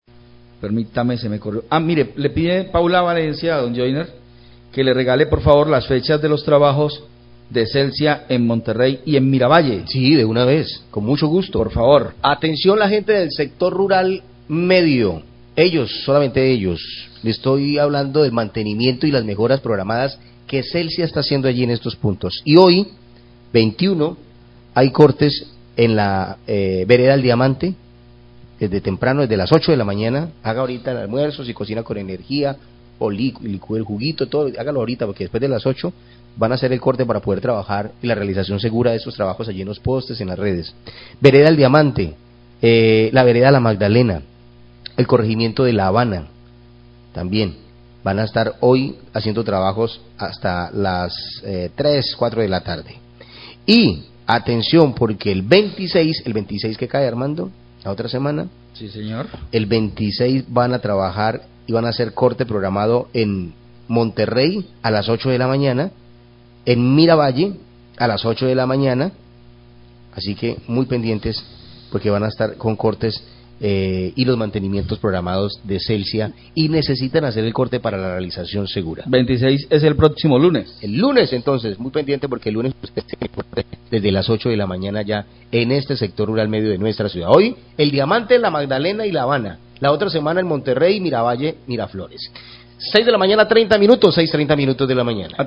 Oyente pide que recuerden los mantenimientos en veredas Monterrey y Miravalle